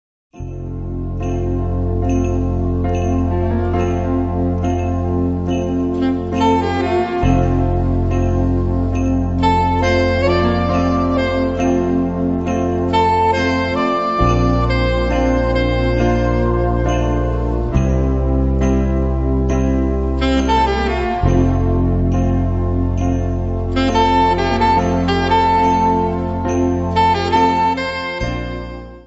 A4 Besetzung: Blasorchester Zu hören auf